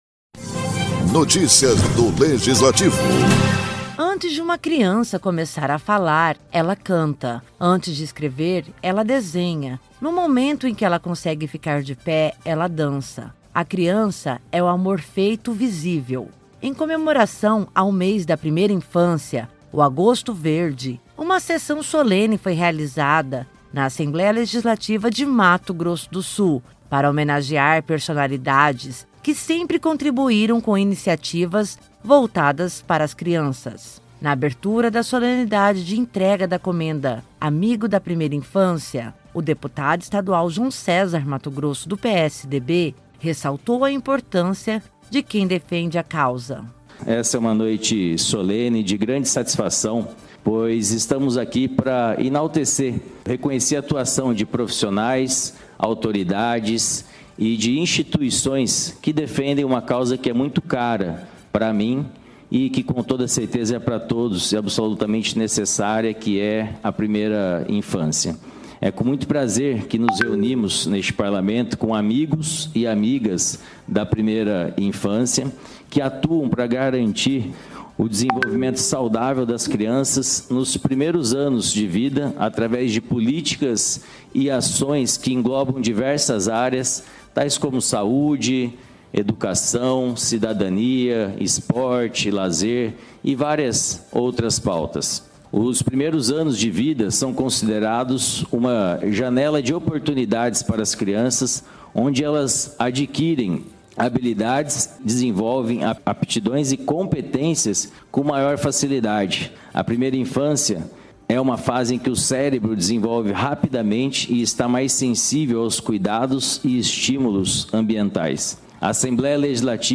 A Assembleia Legislativa de Mato Grosso do Sul realizou sessão solene de entrega da Comenda Amigo da Primeira Infância, proposta pelo deputado João César Mattogrosso (PSDB). O evento, que lotou o Plenário Júlio Maia, entregou a honraria a 20 homenageados que se destacaram na proteção, contribuição ao desenvolvimento integral e garantia dos direitos da primeira infância, que abrange os seis primeiros anos completos da criança.